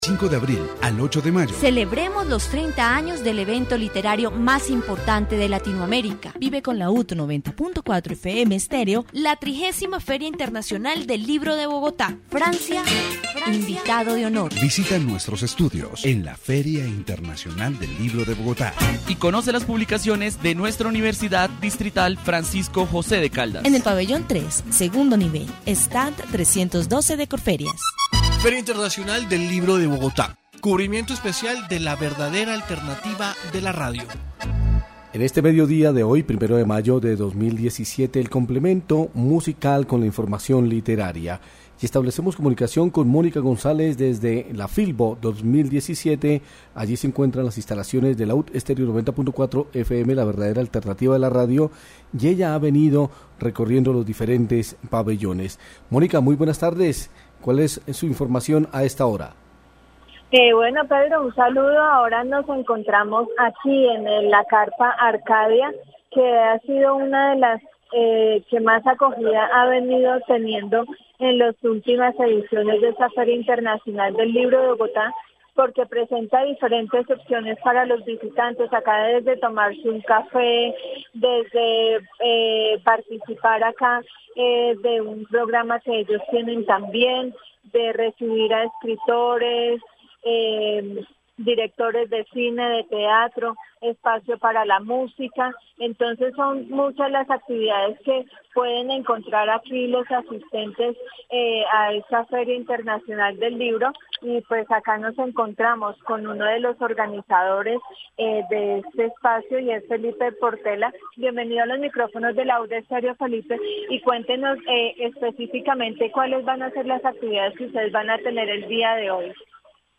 Feria del Libro 2017. Informe radial
Programas de radio